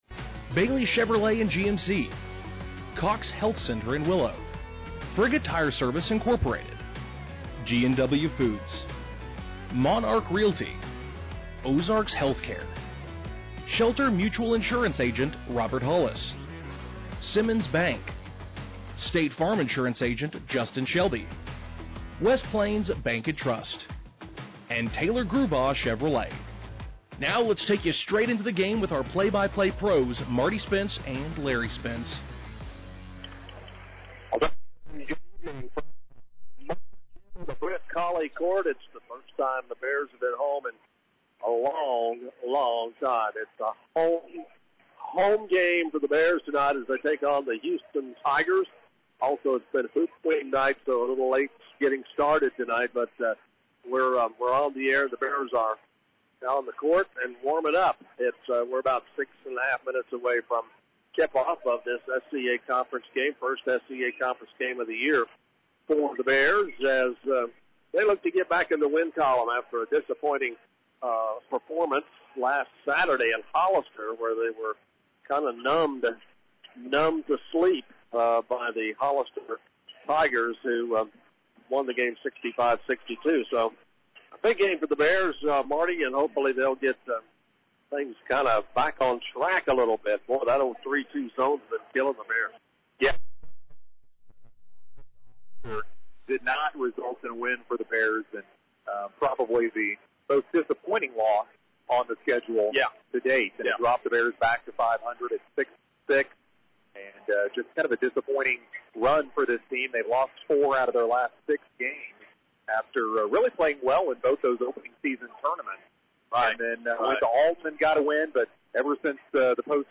Game Audio Below: Quarter 1: Game starts with 3 – nothing Houston with a foul for an extra point. Bears on top 4-3 in the 1st quarter 2 minutes in.